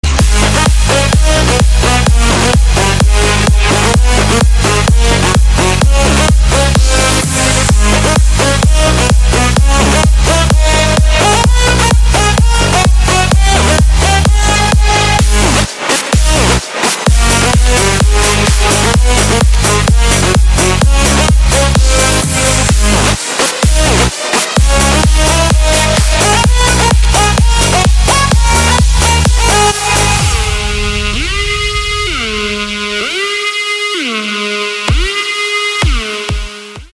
• Качество: 320, Stereo
dance
без слов
club
electro